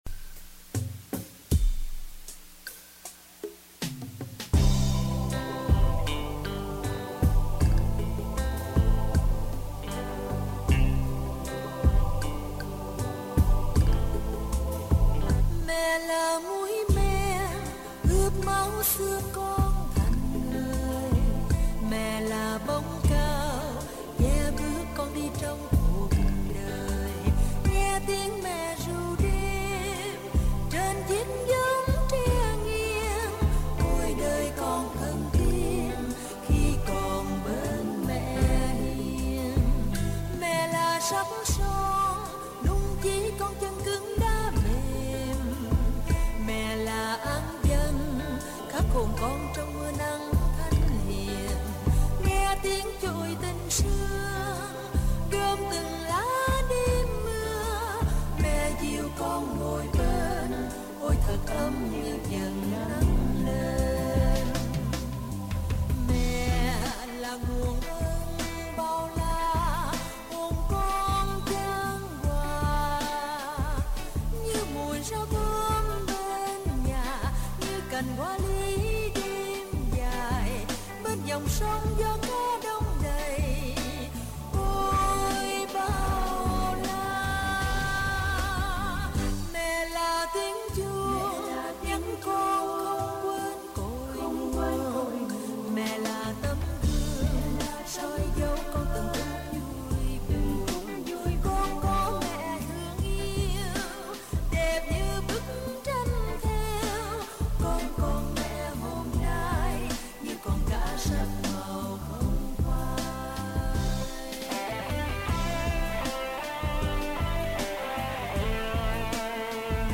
Đọc truyện ngắn - Mẹ yêu - 05/07/2022 | Radio Saigon Dallas - KBDT 1160 AM